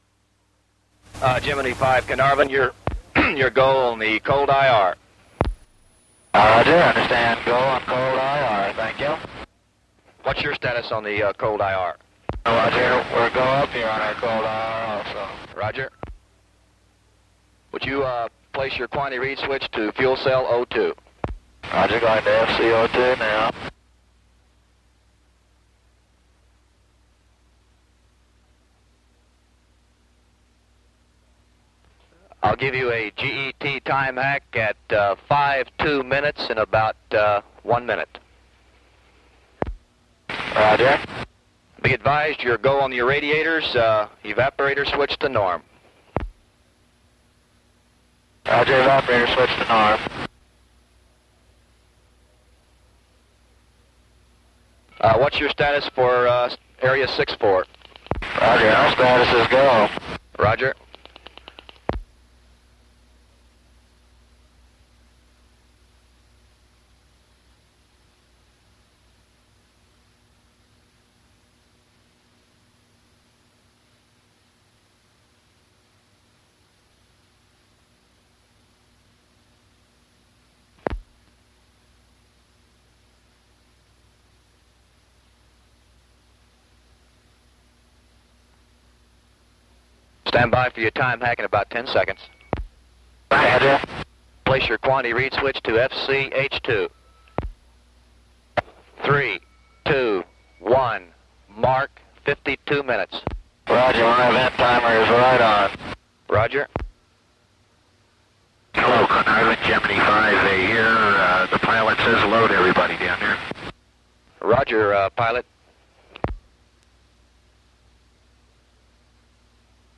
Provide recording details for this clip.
Gemini V audio – recorded at Carnarvon The audio files of each pass are unaltered, however periods of silence between passes have been removed or reduced.